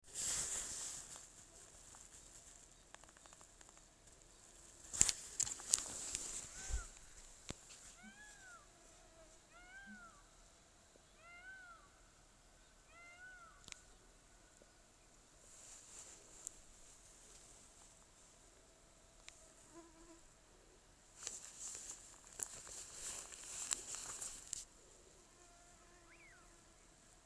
Bird Aves sp., Aves sp.
StatusVoice, calls heard
Pa 4-5 kliedzieniem ik pēc 20-30 min. Laikam kaut kas liels, jo skaņa nāca no otras ezera puses, bet bija skaļi.